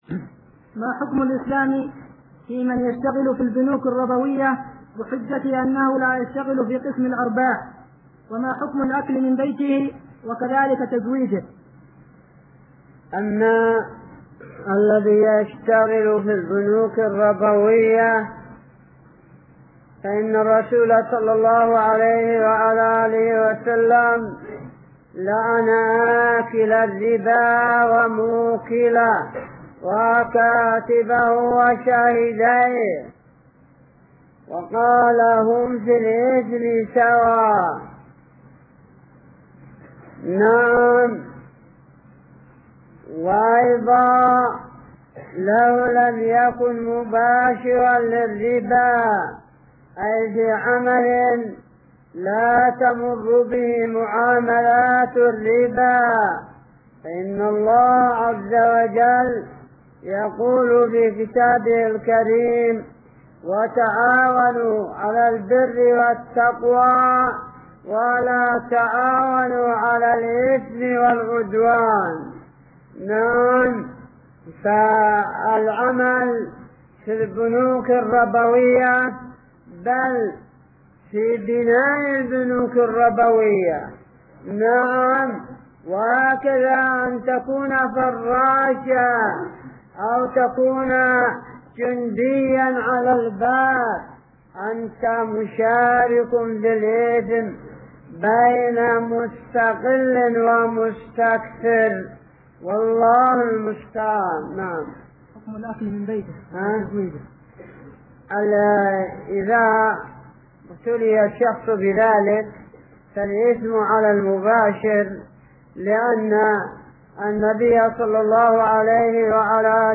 ------------- من شريط : ( أسئلة الأخوة بوادي بن علي بحضرموت )